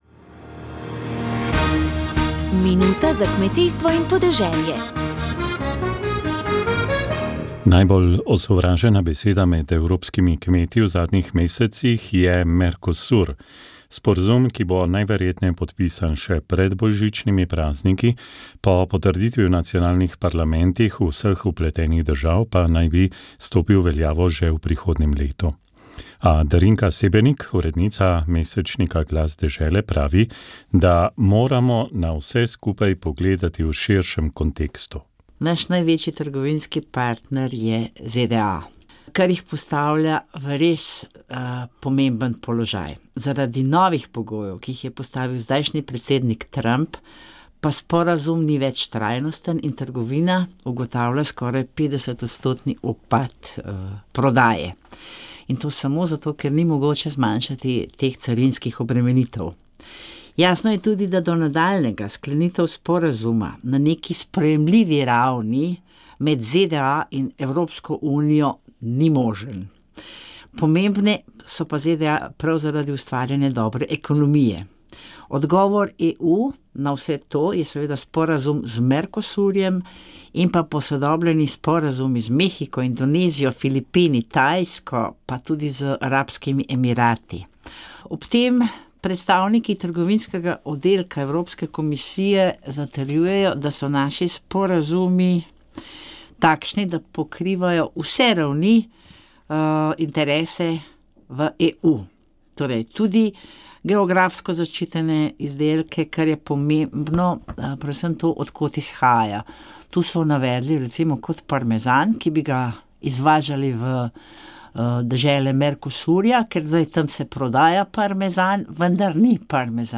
Sveta maša